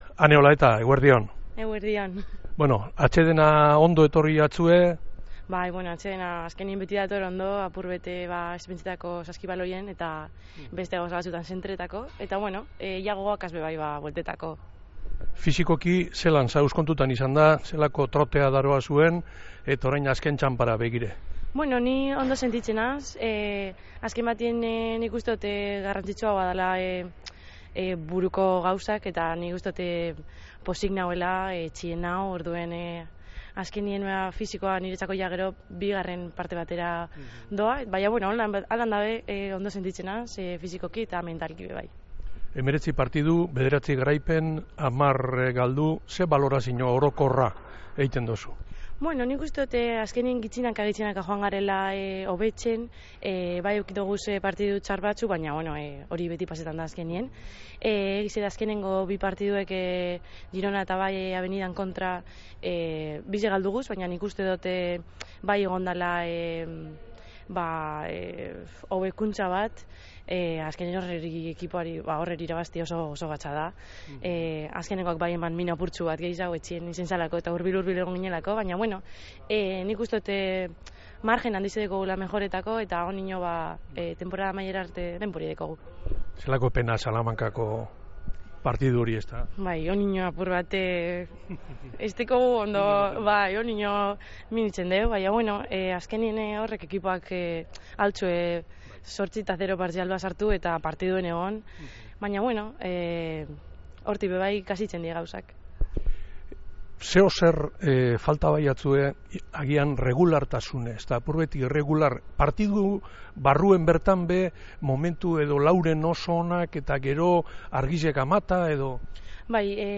Beragaz egin dogu berba Bizkaia Irratiko Jokoan kirol tartean.